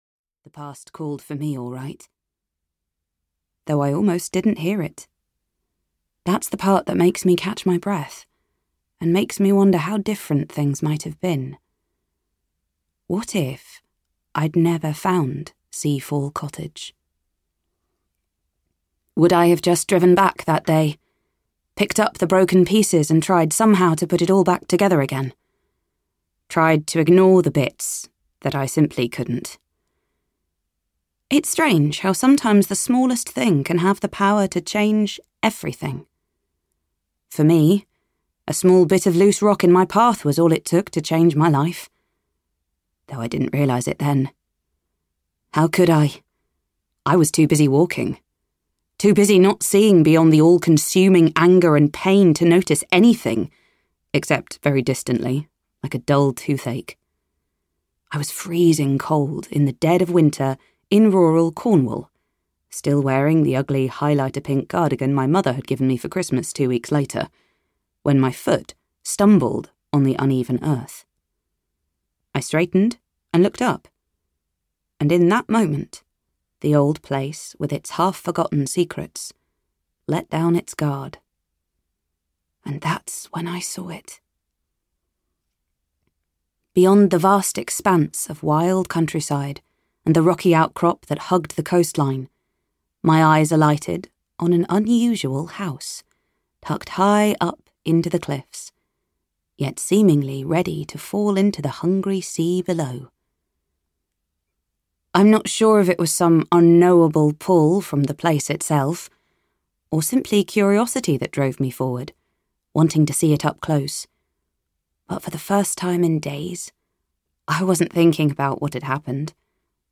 Summer at Seafall Cottage (EN) audiokniha
Ukázka z knihy